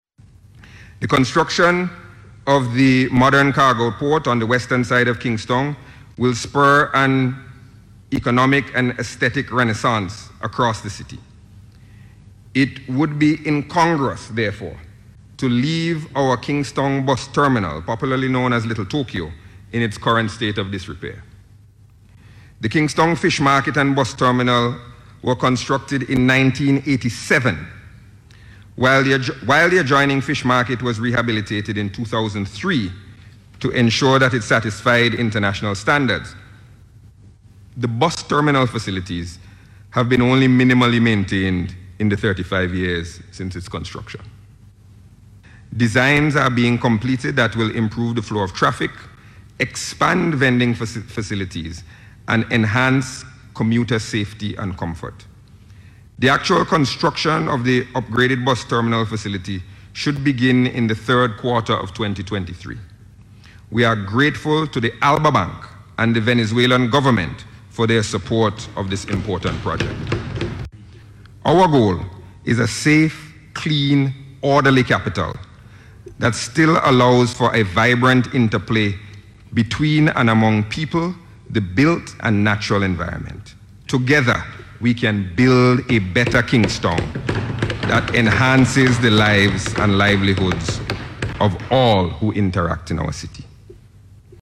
Delivering the 2023 Budget in Parliament on Monday, Minister of Finance Camillo Gonsalves said there are allocations totaling 3.1 million dollars to begin the rehabilitation.